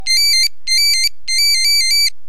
Motorola old